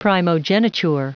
Prononciation du mot primogeniture en anglais (fichier audio)
Prononciation du mot : primogeniture